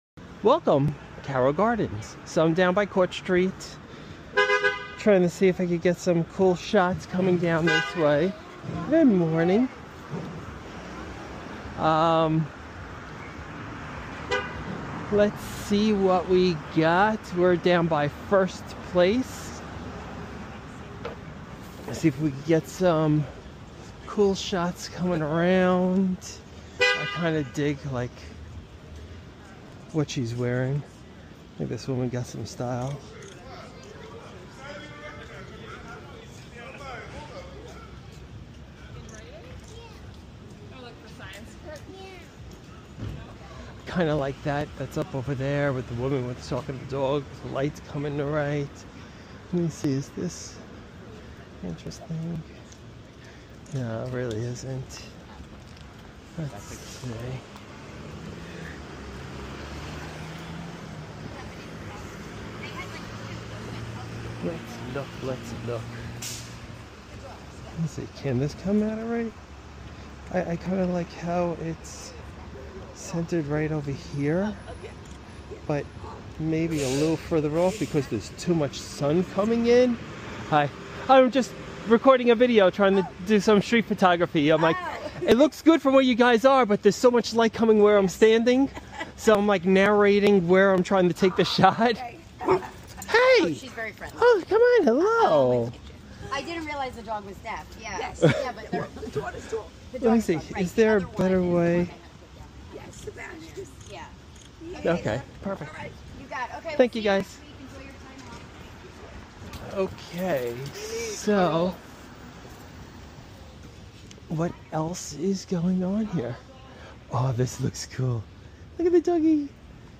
Street photography video in Carroll Gardens at Court Street, Brooklyn at 8am.